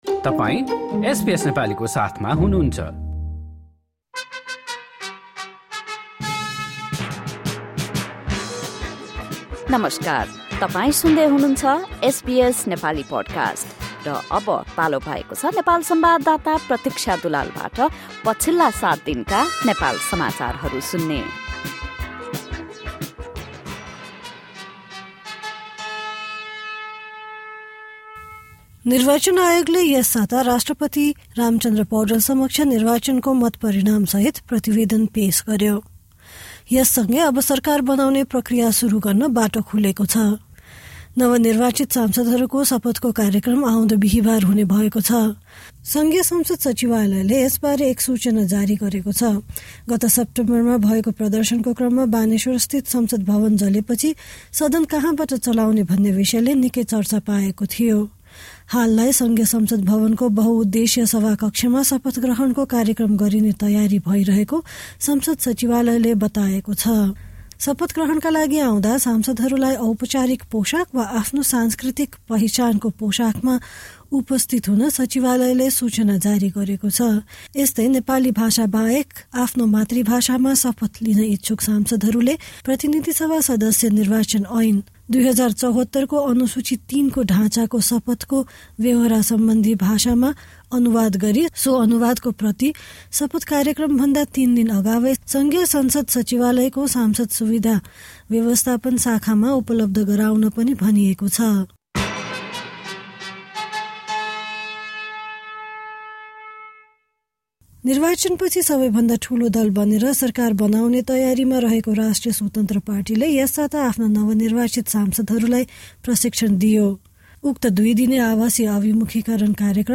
Listen to our weekly report on the major news in Nepal over the past seven days.